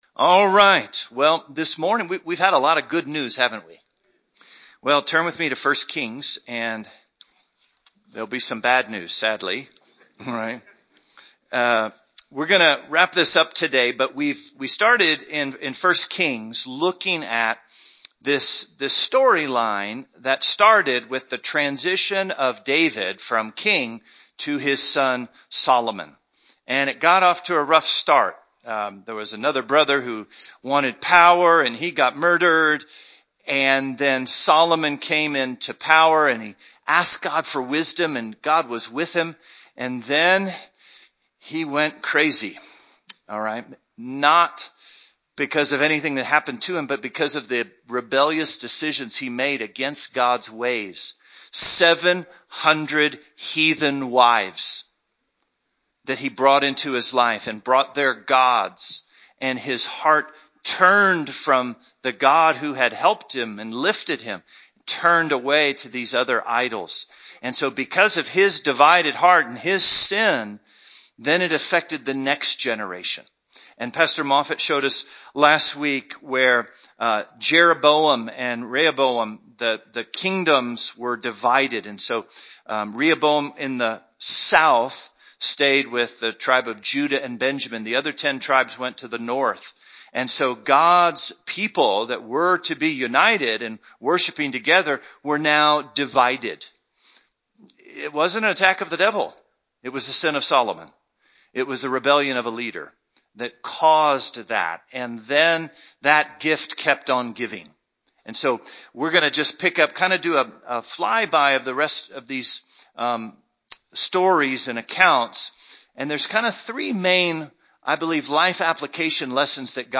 A message from the series "Kings Survey."